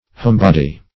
homebody \home"bod`y\ n.